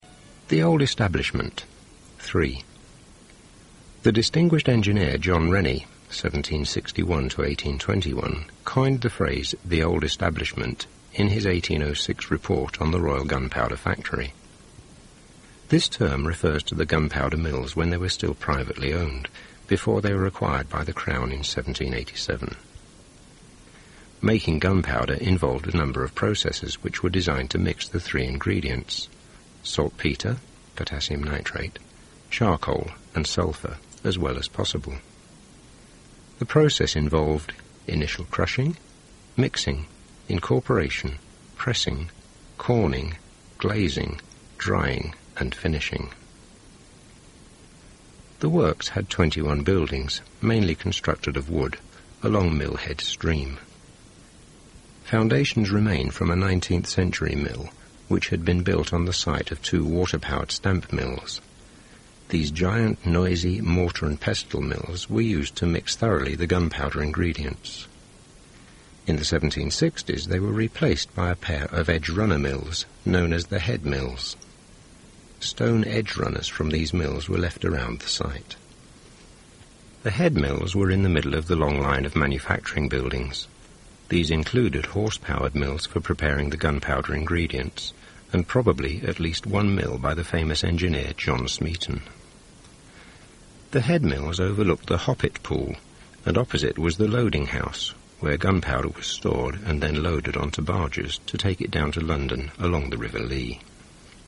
Subject: WARGM Audio Tour 2001 Description: Old Establishment
Format: Cassette Tape